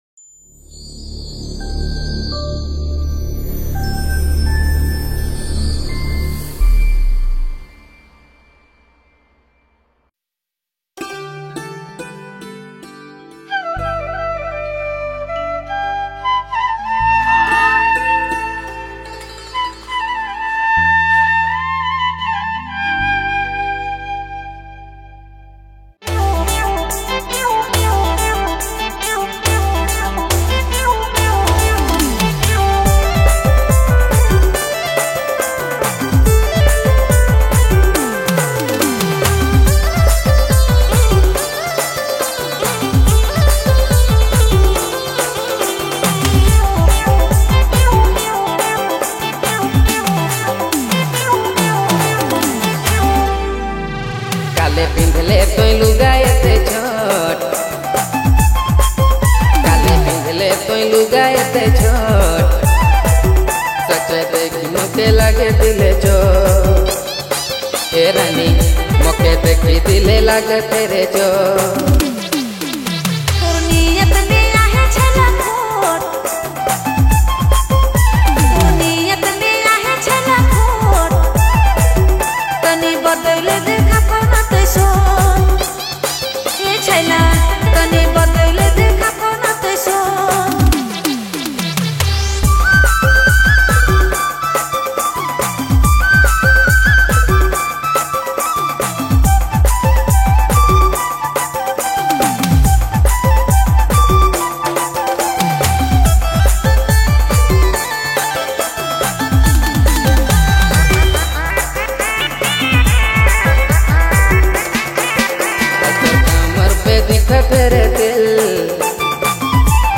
Releted Files Of Nagpuri